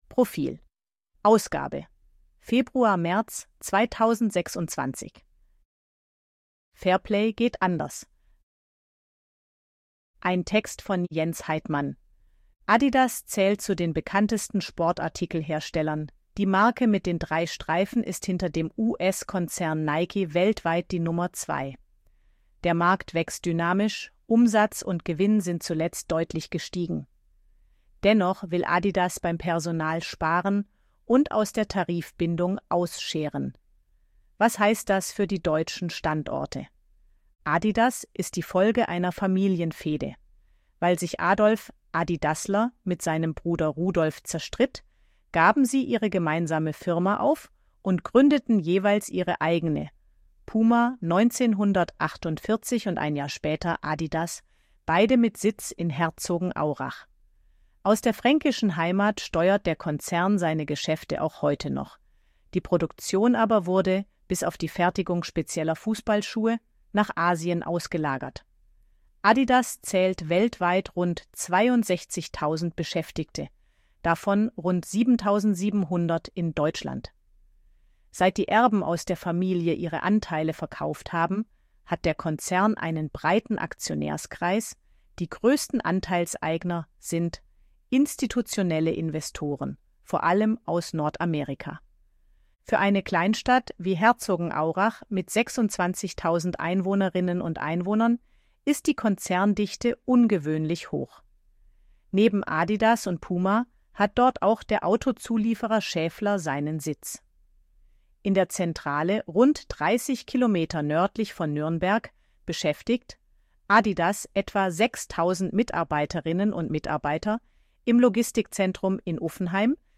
ElevenLabs_261_KI_Stimme_Frau_AG-Check.ogg